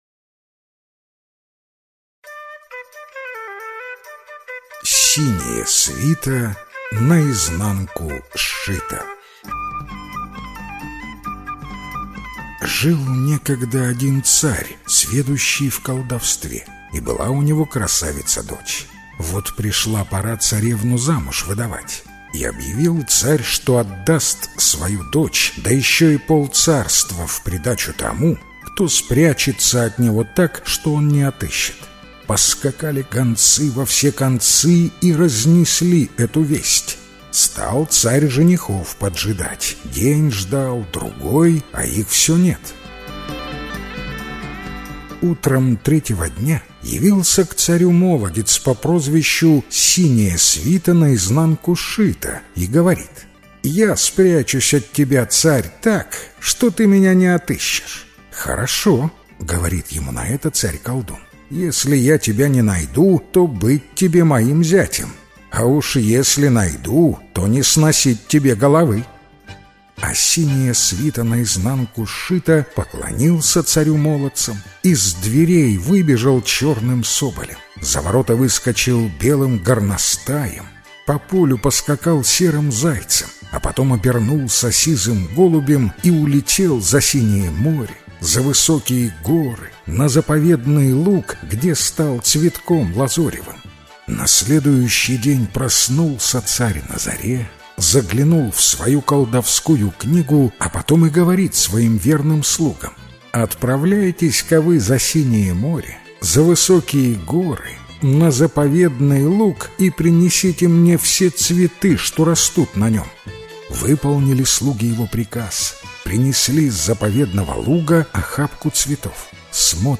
Синяя свита наизнанку сшита – белорусская аудиосказка